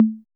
808 CGA LO.wav